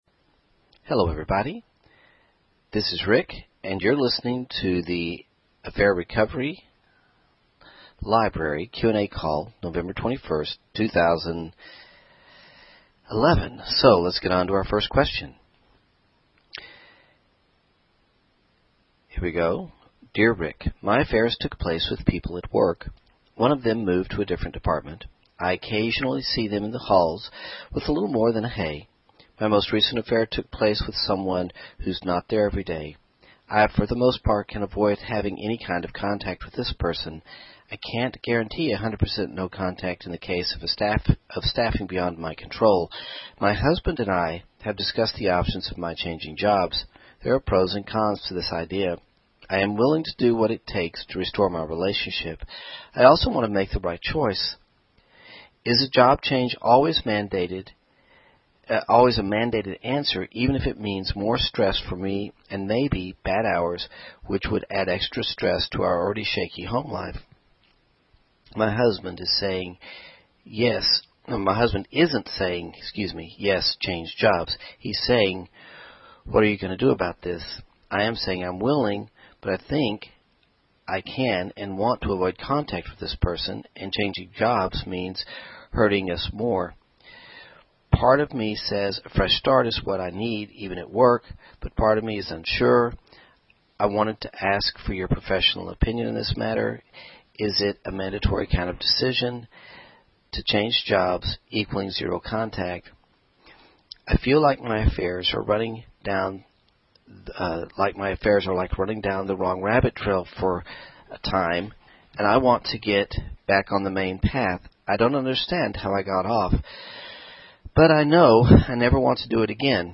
Q & A Call